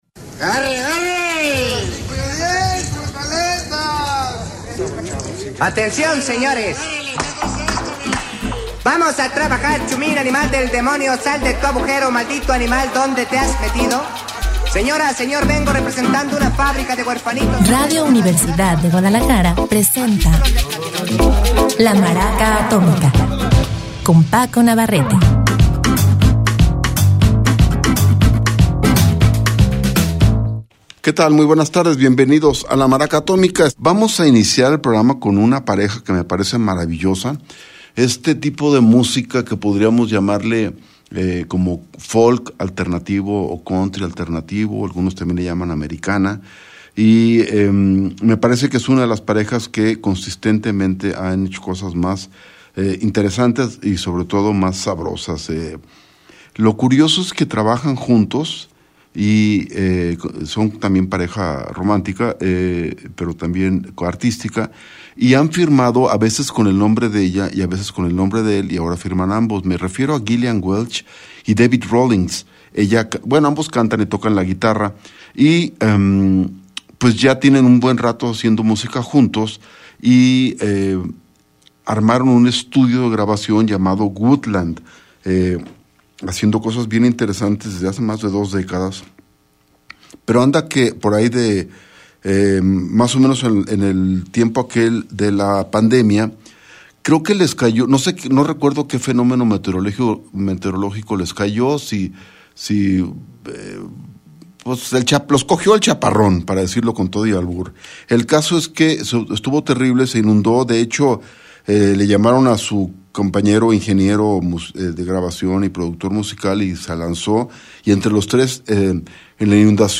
empezamos con una pareja de folk alternativo o Americana